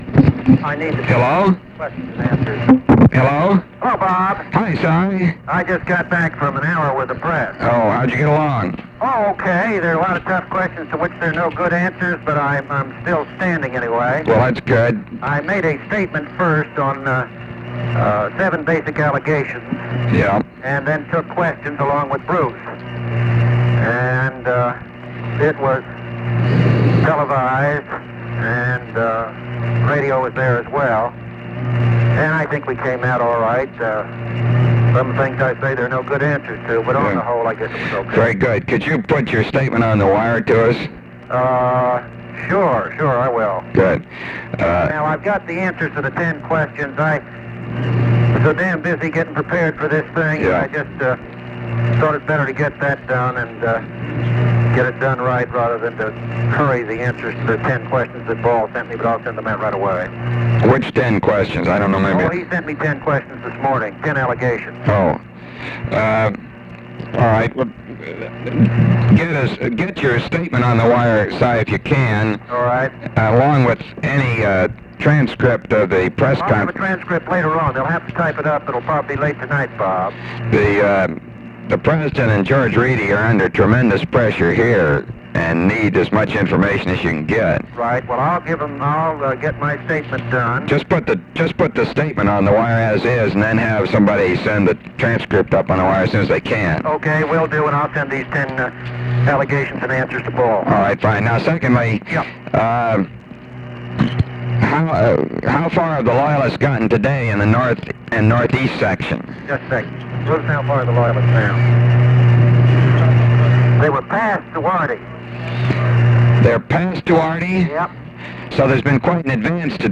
Conversation with ROBERT MCNAMARA
Secret White House Tapes